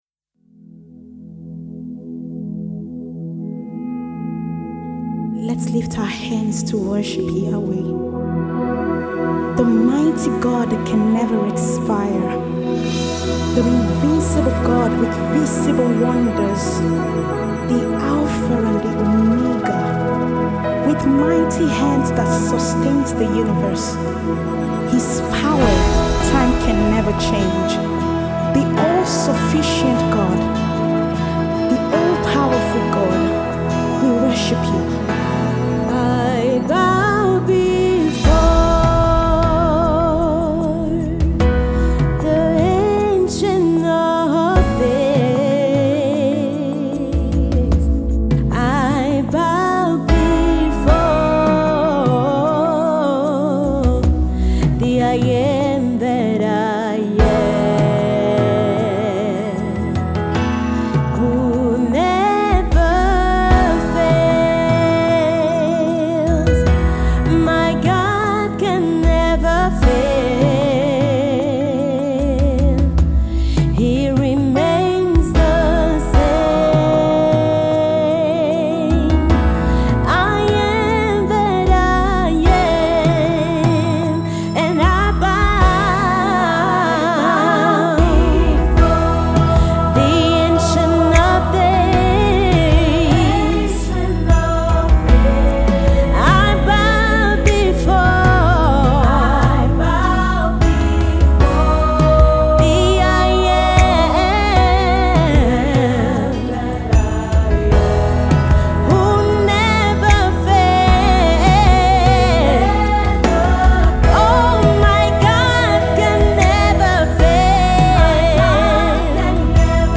a song of adoration to God